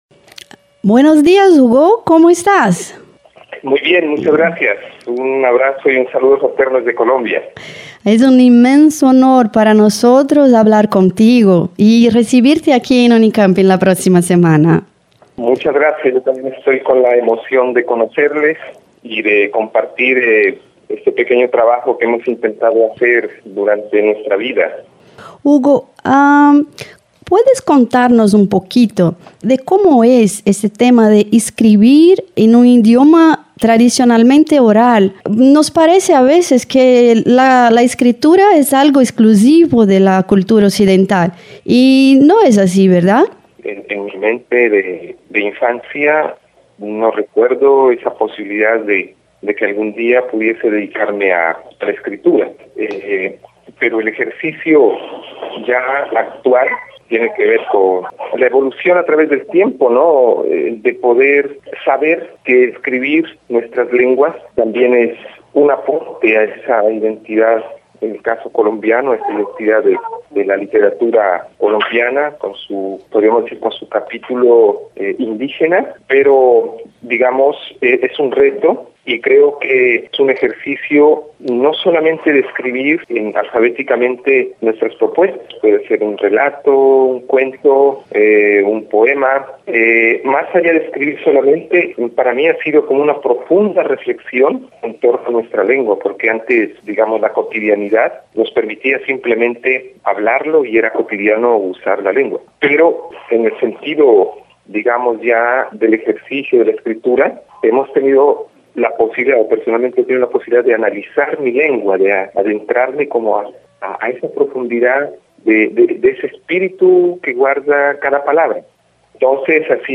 Leia alguns trechos da entrevista realizada pela Diretoria de Comunicação da Proec e confira o áudio da entrevista em espanhol . Como é o processo de escrita de uma língua de tradição oral?